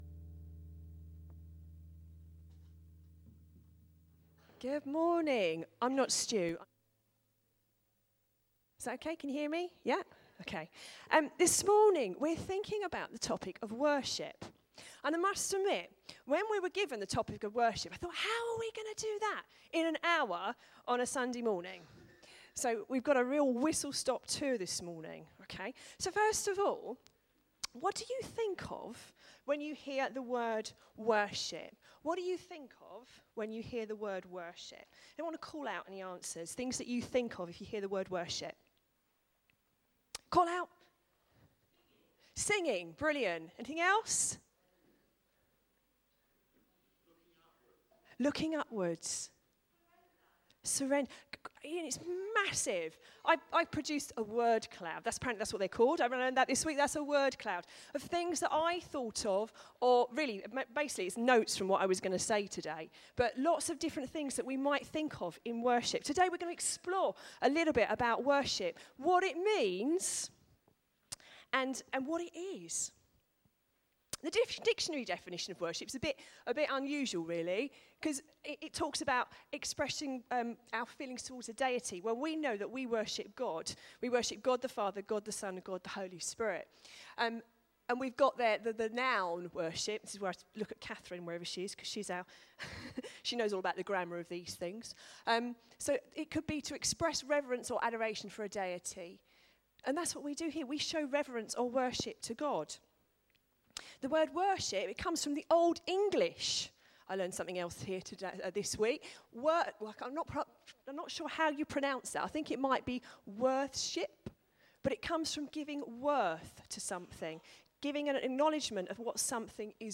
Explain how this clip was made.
From Series: "Family Service"